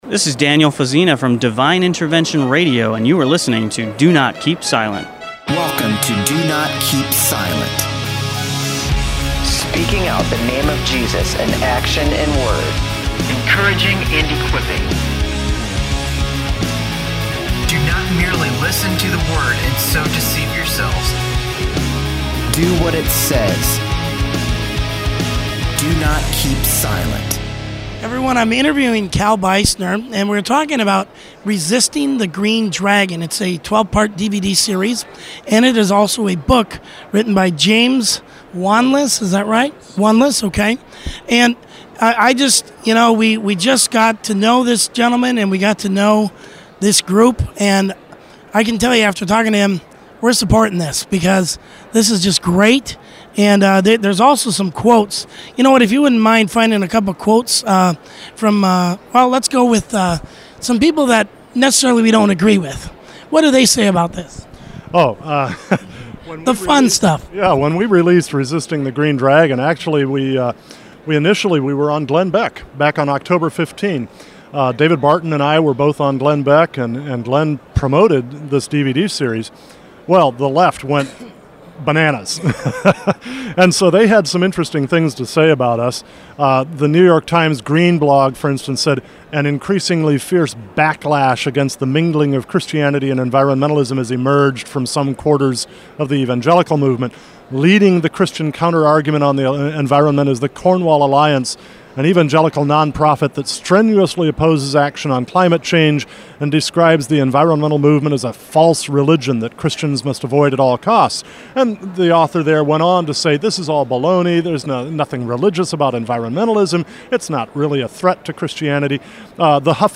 during the “Voices in the Night” tour. They discuss I Corinthians 13 and wrestle with some very touchy subjects during this interview, such as prophecy and even speaking in tongues.